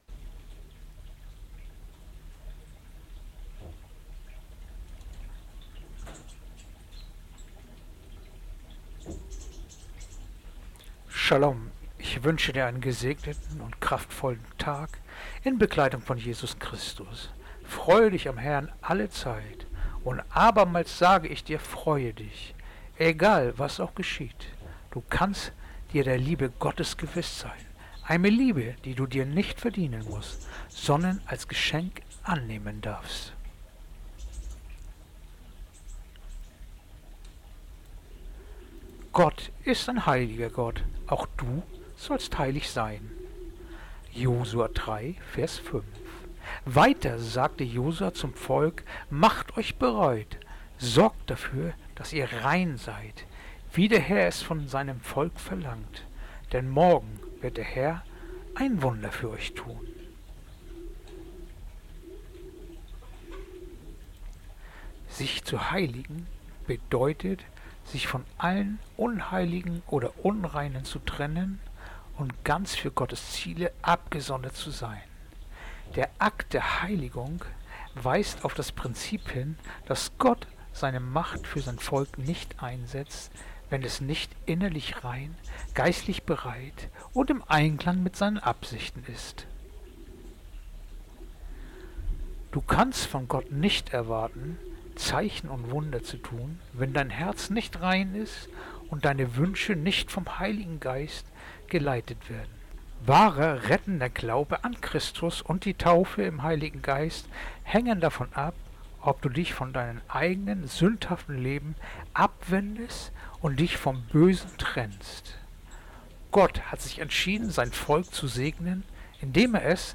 Andacht-vom-16.-Februar-Josua-3-5